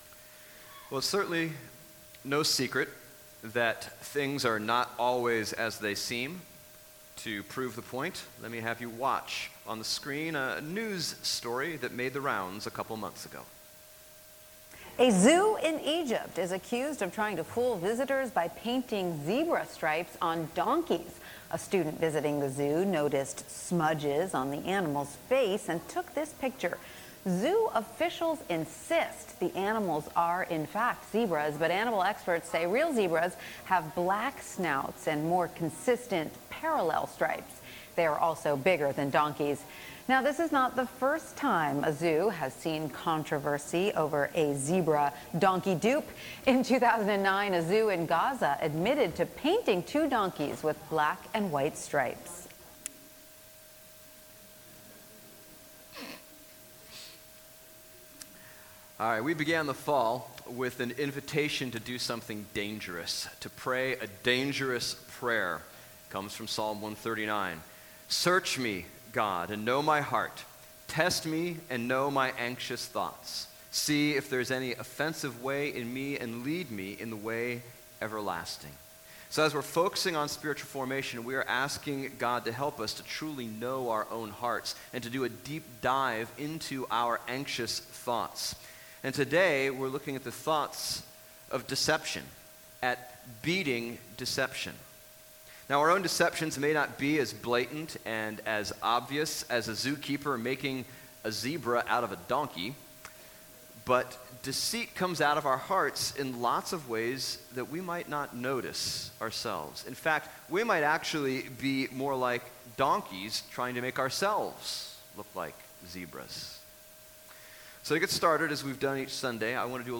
Bible Text: Matthew 23:1-7 | Preacher